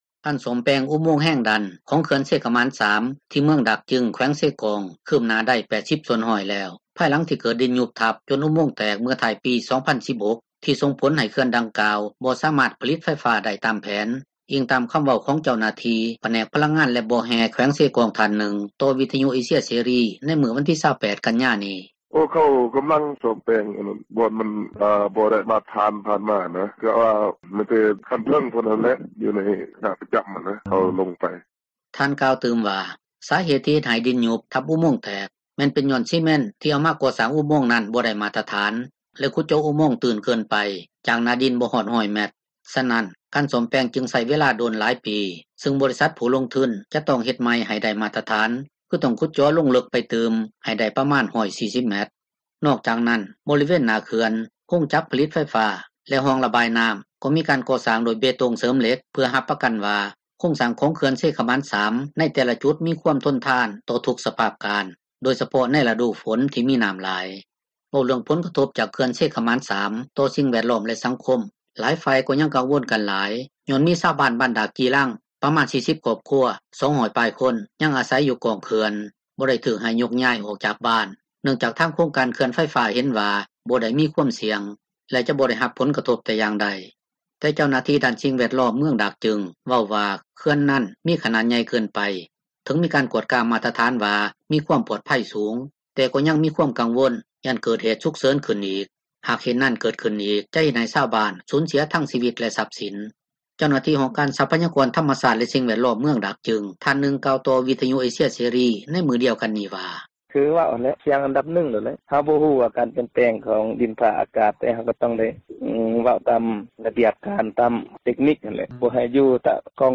ຊາວບ້ານຢູ່ ເມືອງດາກຈຶງ ຜູ້ນຶ່ງເວົ້າຕໍ່ວິທຍຸເອເຊັຽເສຣີ ໃນມື້ວັນທີ 28 ກັນຍານີ້ວ່າ:
ແລະ ຊາວບ້ານອີກຄົນນຶ່ງ ຢູ່ເມືອງດາກຈຶງ ກໍເວົ້າວ່າ ບໍ່ຢາກໃຫ້ມີການ ສ້າງເຂື່ອນອີກ ແຕ່ກໍຄັດຄ້ານຫຍັງບໍ່ໄດ້, ຮອດຣະດູຝົນປີໃດ ກໍກັງວົນ ນໍາເຣື່ອງນໍ້າຖ້ວມຢູ່ຕລອດ: